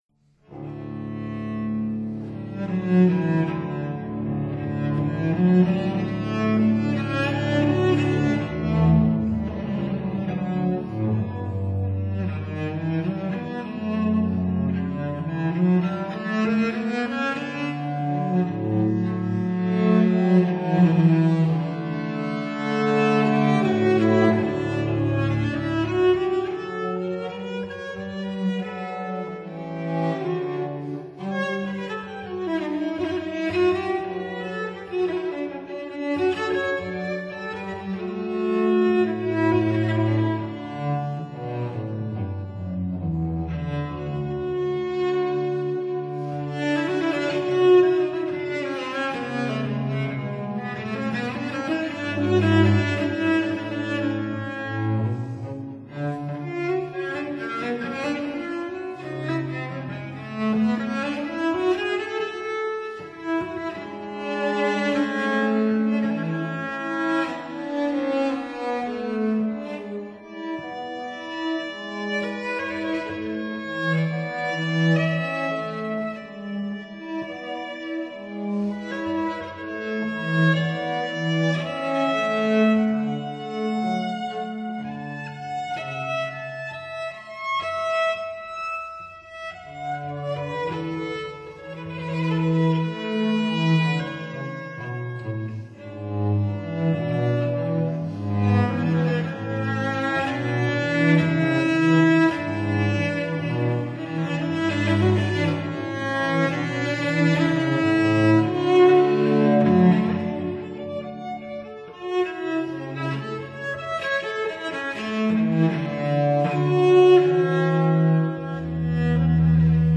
Cello Sonatas Op. 4 Nos. 1, 2, 5 & 6)
Cello Duos  Nos. 2 & 3
Cellos
(Period Instruments)